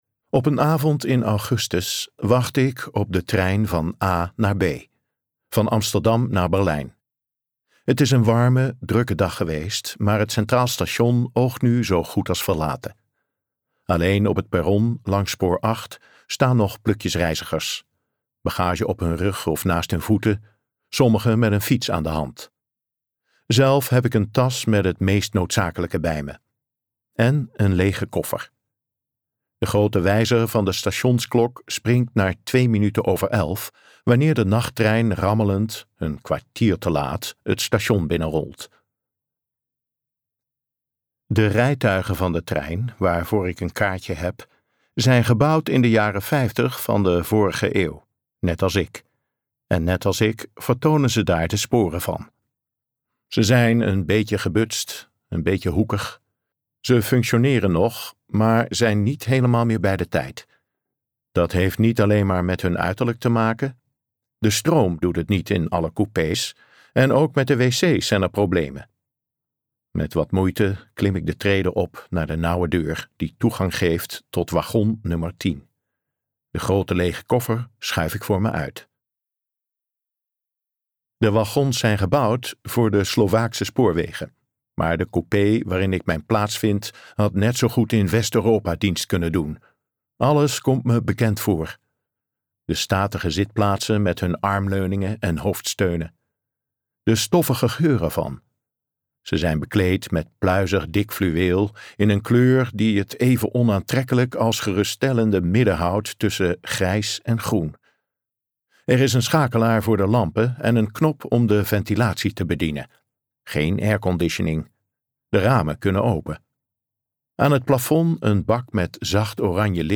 Ambo|Anthos uitgevers - Nachttrein naar berlijn luisterboek